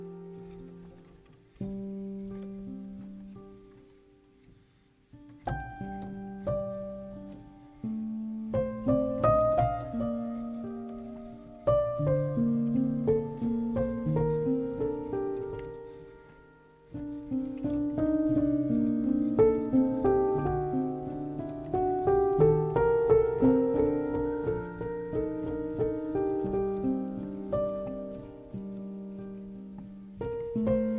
paysages ambient
les éléments percussifs et électroniques de son travail
créer son art sur scène